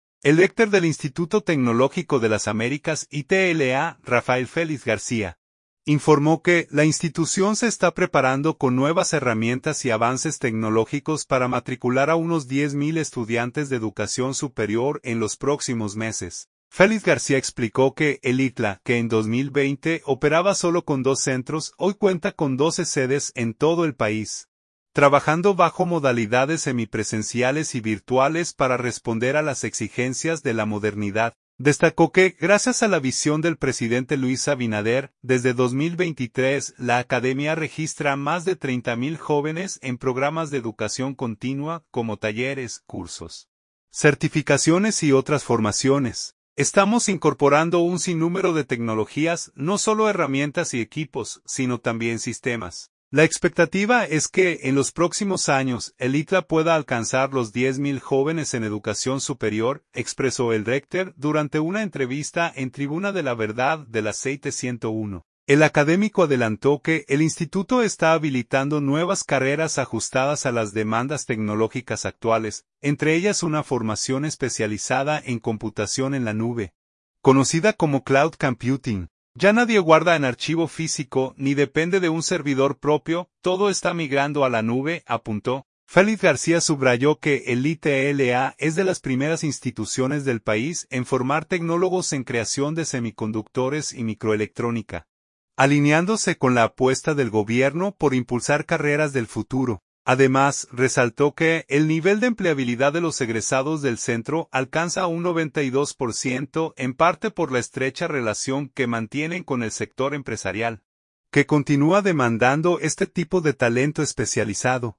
“Estamos incorporando un sinnúmero de tecnologías, no solo herramientas y equipos, sino también sistemas. La expectativa es que, en los próximos años, el ITLA pueda alcanzar los 10,000 jóvenes en educación superior”, expresó el rector durante una entrevista en Tribuna de la Verdad, de la Z101.